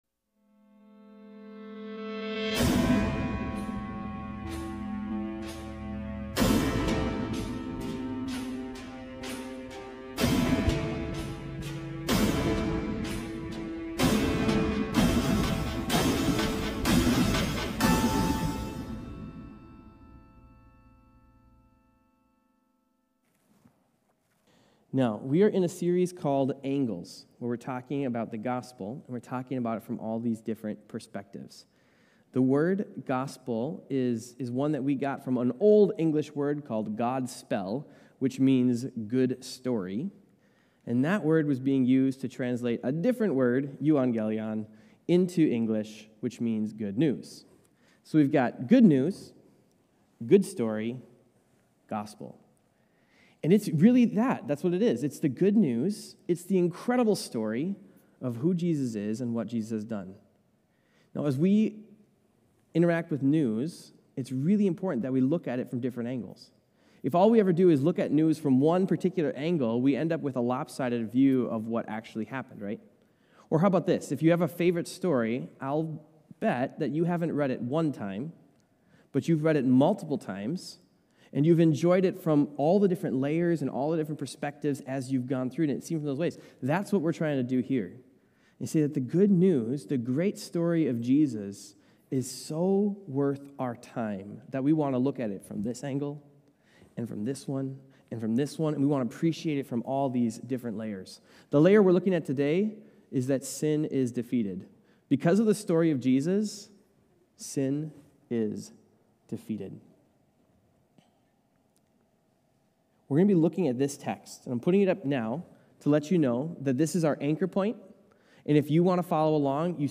Sin-is-Defeated-Sermon-3.23.25.m4a